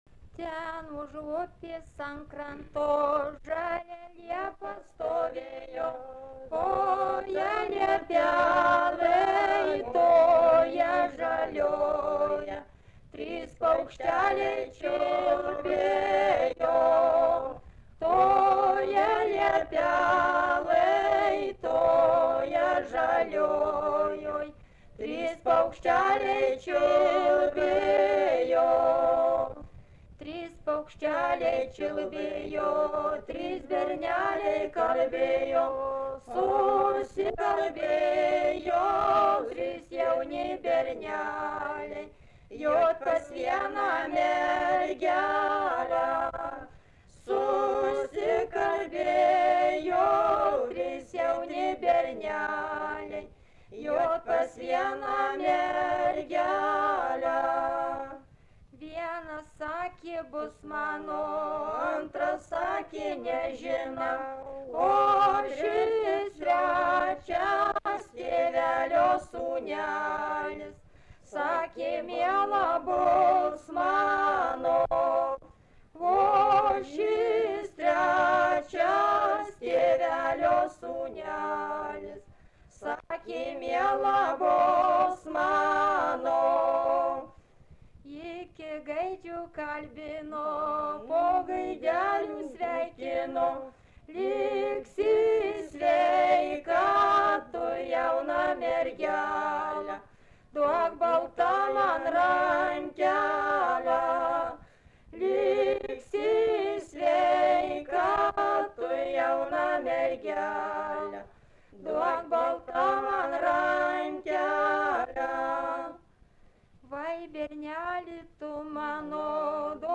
Dalykas, tema daina
Erdvinė aprėptis Stakliškės
Atlikimo pubūdis vokalinis